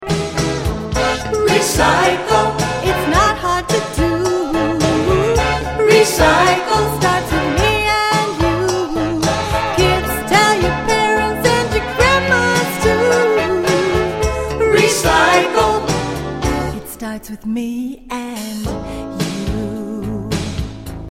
A Conservation Song